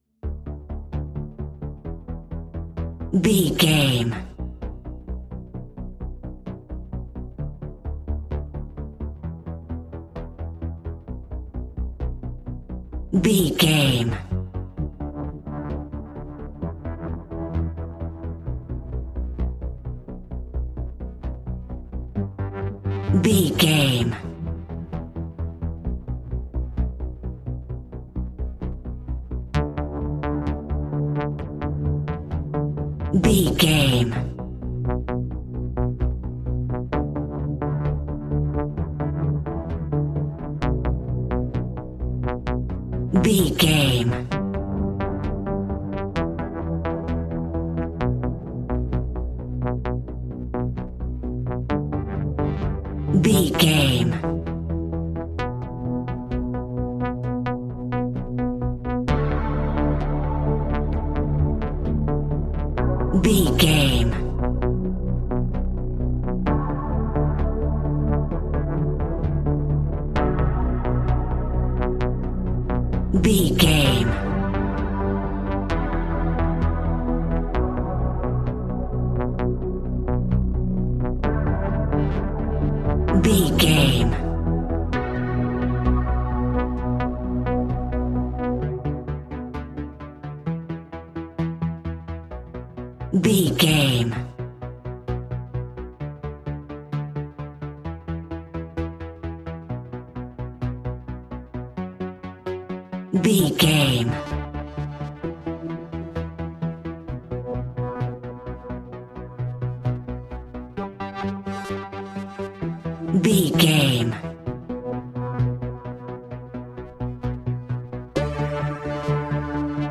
In-crescendo
Aeolian/Minor
ominous
haunting
eerie
synthesiser
horror music
Horror Pads
Horror Synths